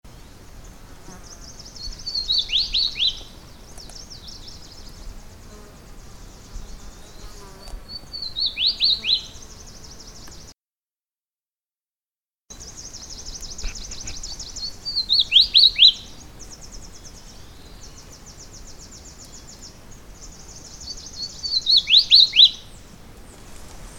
With my LS-11 Olympus voice recorder I made the following recordings of bird songs, all in stereo: